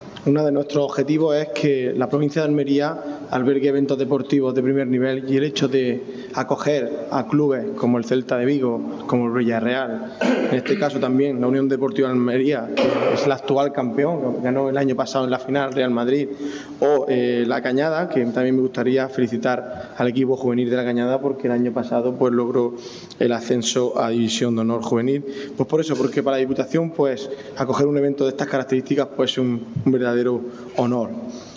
La cita, que reunirá este año a más de cien futbolistas, se ha presentado esta mañana en la sala de prensa del Ayuntamiento de Almería, en la Plaza Vieja.